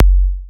edm-kick-83.wav